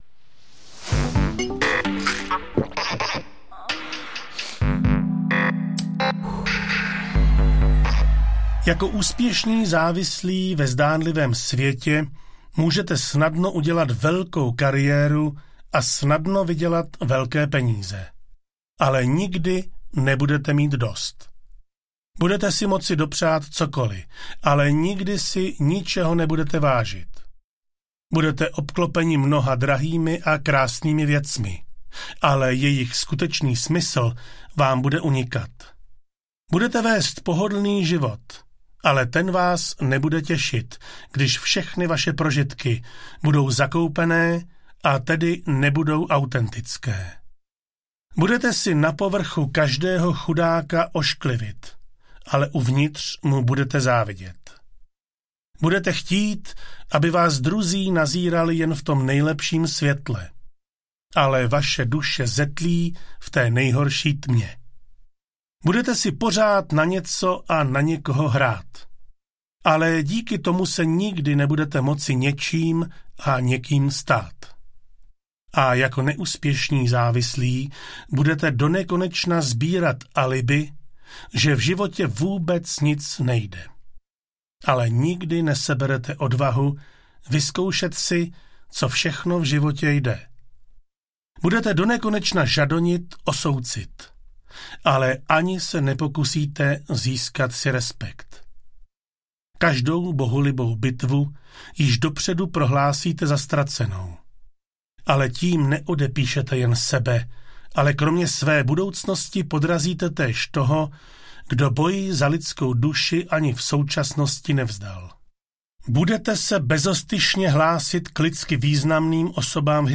Psychoterapie lidské závislosti zdravým rozumem audiokniha
Ukázka z knihy
psychoterapie-lidske-zavislosti-zdravym-rozumem-audiokniha